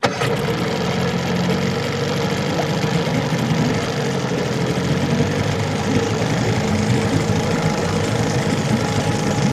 50 hp Evinrude Boat Start Loop, Start and Idle